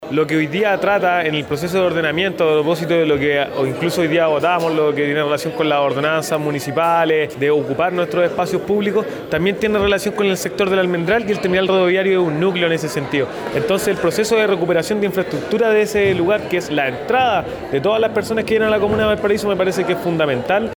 En medio de la reciente votación, además de la ordenanza municipal que busca mejorar la seguridad en el barrio Almendral, donde está precisamente el Terminal Rodoviario de Valparaíso, el concejal Lukas Cáceres catalogó las obras como un proceso de recuperación fundamental.